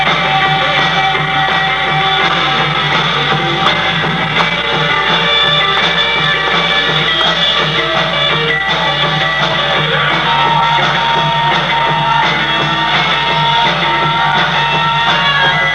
Joe Don's Guitar Solo (1)
joedonrooney-guitarsolo2_2.wav